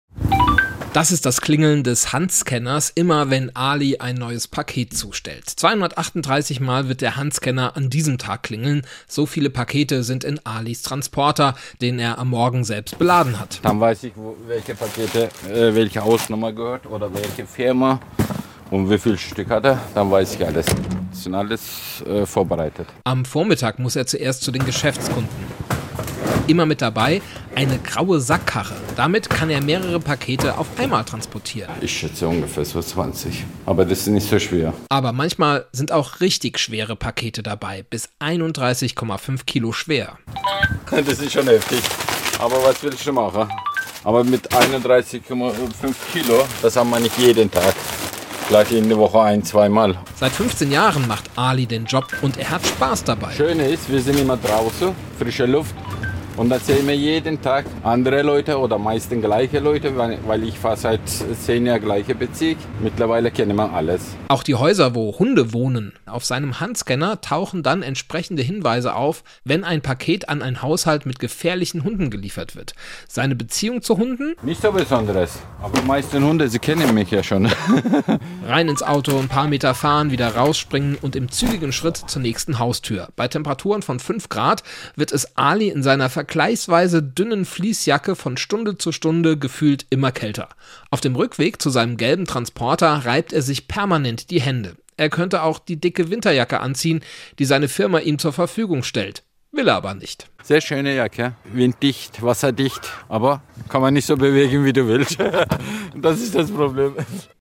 in Karlsruhe-Neureut unterwegs. Wie hart der Job sein kann, hört ihr hier: